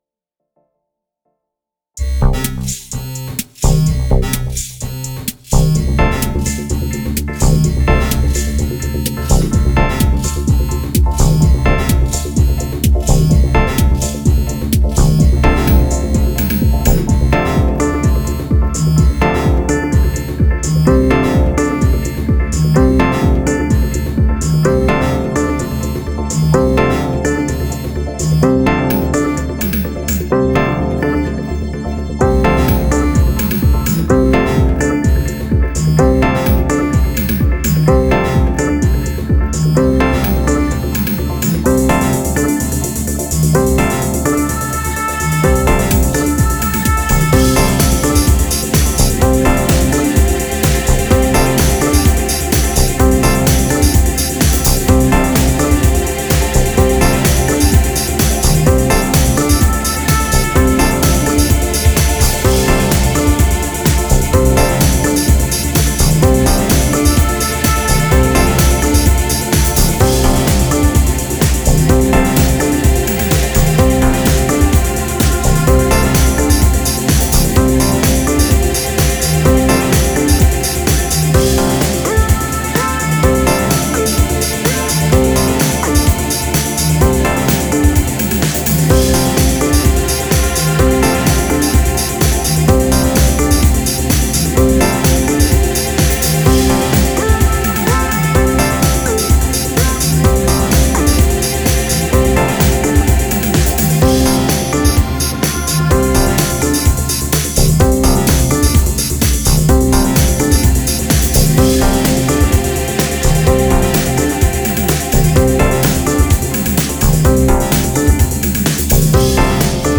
Genre Experimental